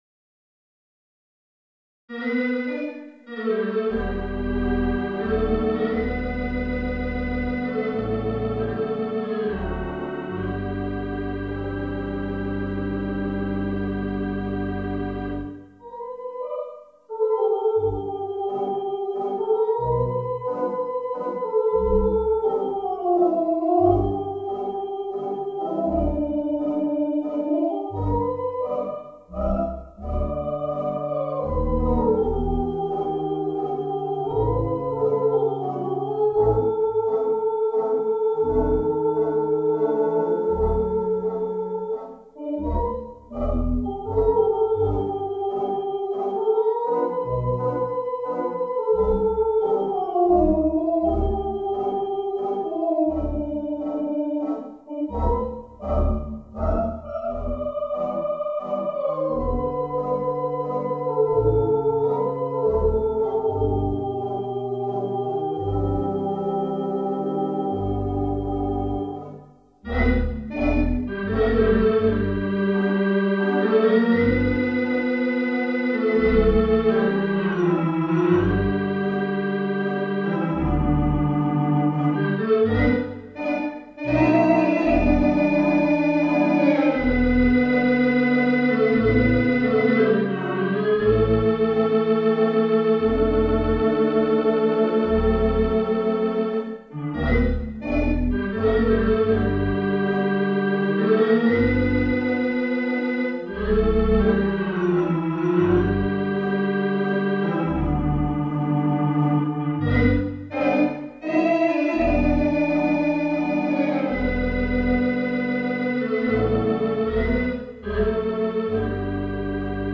Virtual Theatre Pipe Organ